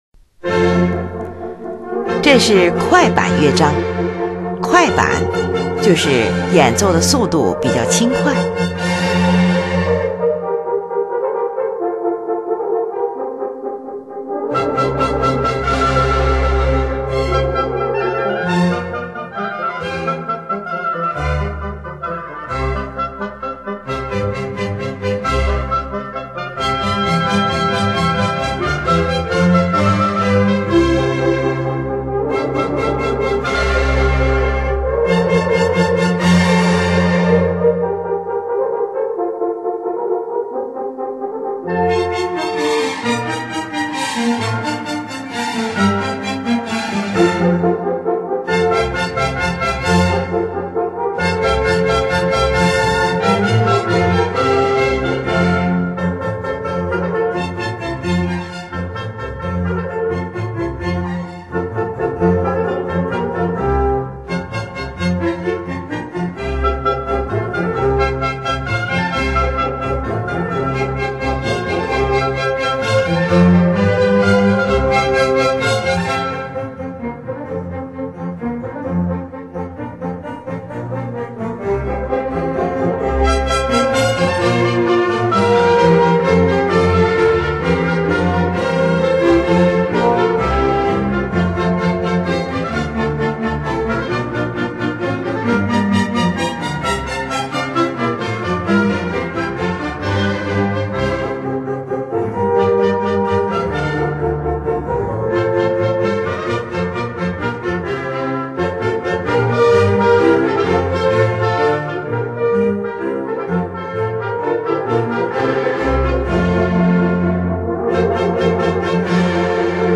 2）这是快板乐章。快板，用轻快的速度演奏的乐章。
是一部管弦乐组曲
乐器使用了小提琴、低音提琴、日耳曼横笛、法兰西横笛、双簧管、圆号、小号等